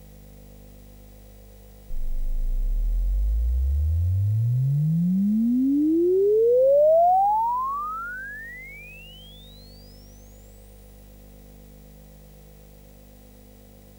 sid-measurements - SID chip measurements